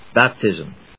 音節bap・tism 発音記号・読み方
/bˈæptɪzm(米国英語)/